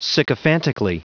Prononciation du mot sycophantically en anglais (fichier audio)
Prononciation du mot : sycophantically